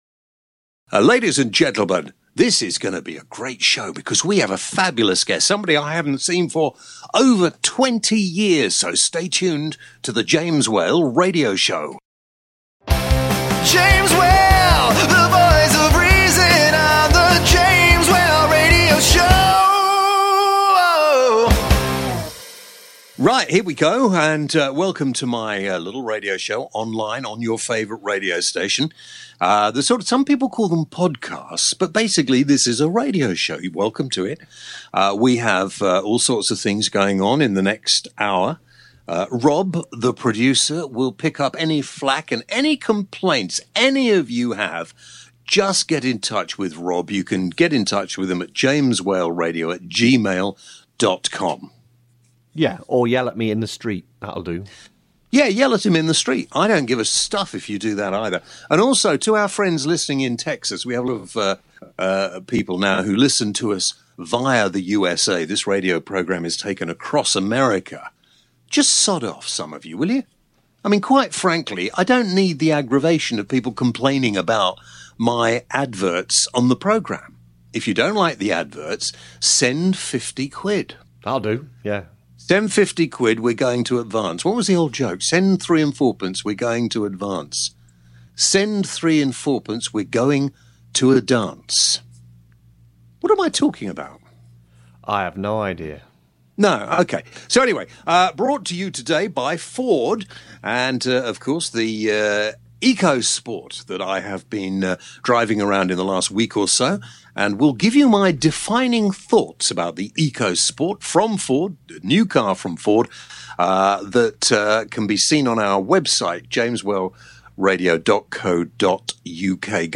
This week James interviews the one and only Wayne Hussey! Plus james launches a new feature called “Tech Talk”. Also on the show a chat about the Ebola virus, do we need pandas, and should we go to in Morocco?